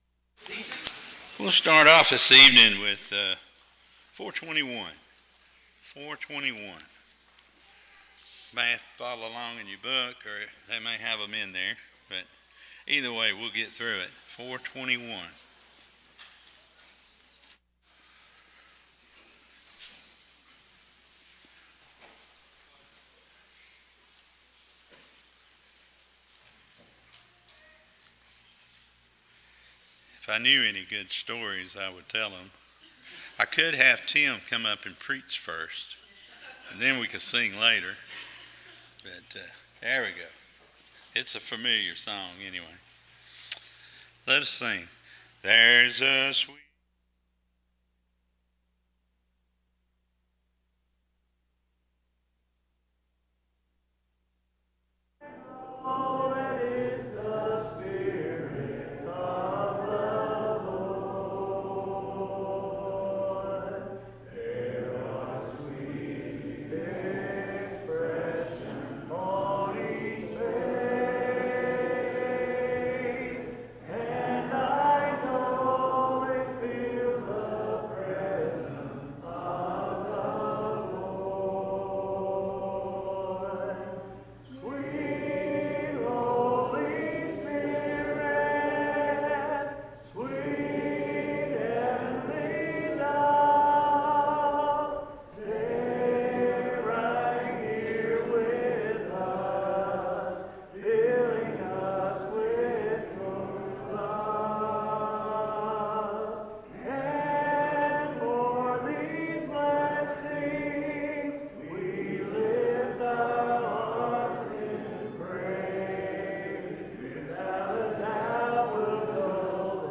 James 3:15, English Standard Version Series: Sunday PM Service